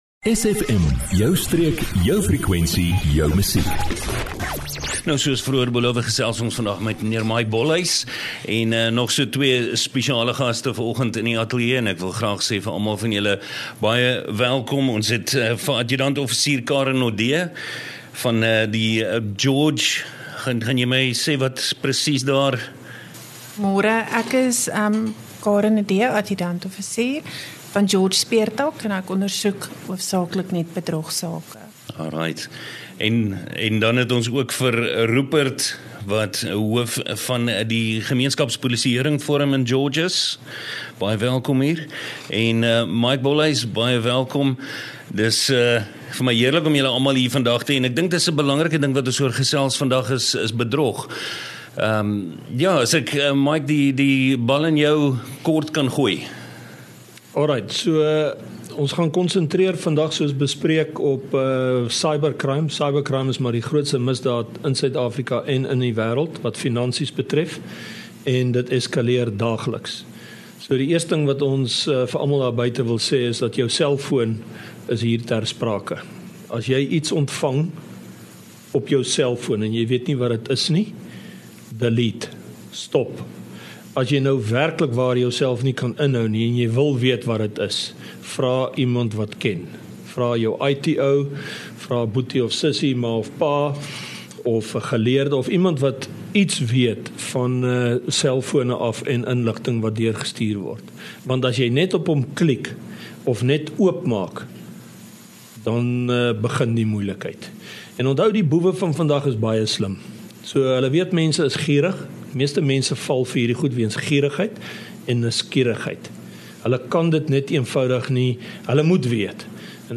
luister weer na die insiggewende gesprek rondom Kuber misdaad en misdaad in die SFM Streek.